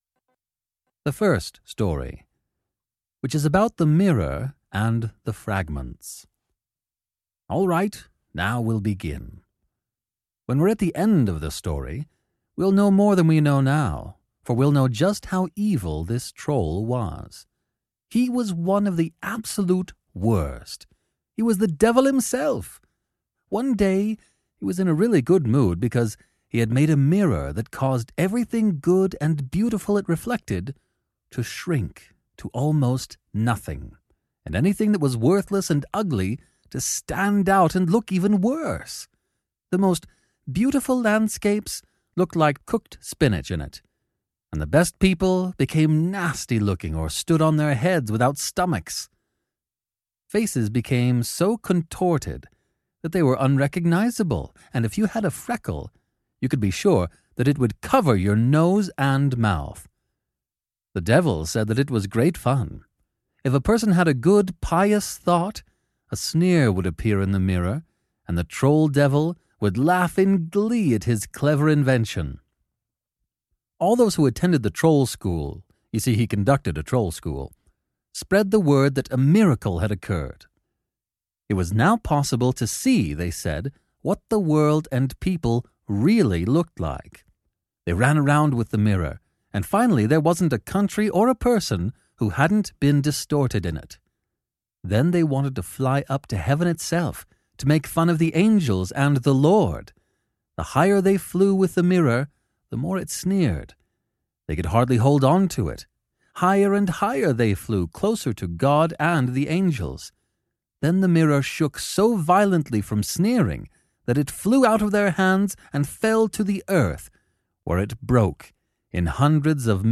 audiokniha
Ukázka z knihy